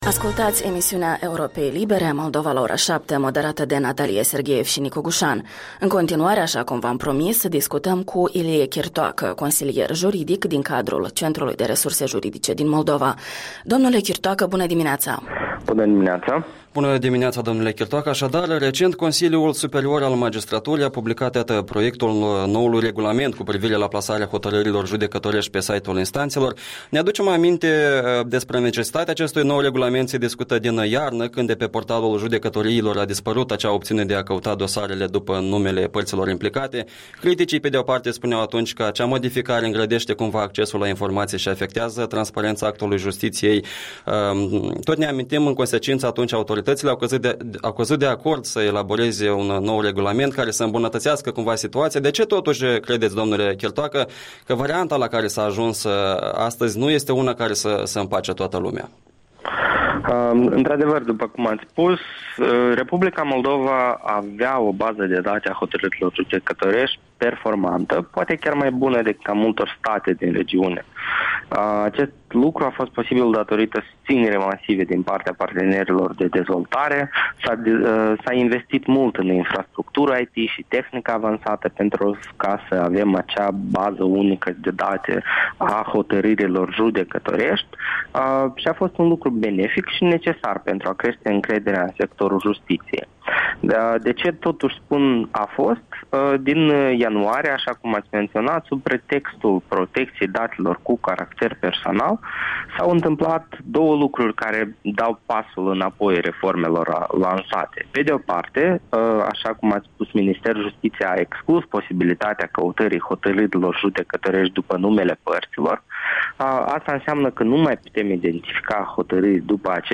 Interviul dimineții despre noul regulament de publicare a hotărârilor judecătorești cu un consilier de la Centrul de Resurse Juridice.